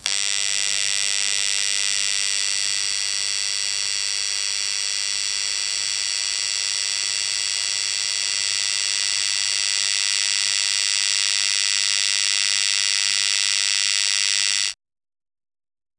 Electric signal headphone's close
electric-signal-headphone-2gnbqcsk.wav